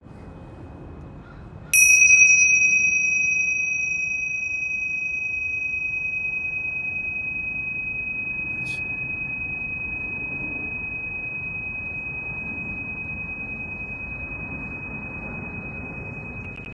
Tingsha Bells
Tingsha are two small metal cymbals stuck together to produce a clear and high-pitched tone. Different size tingsha produces different frequencies – large size tingsha emits a low-toned sound that vibrates for a long period whereas smaller size tingsha provides a high-pitched, pure sound.
65mm-tingsha.m4a